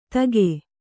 ♪ tage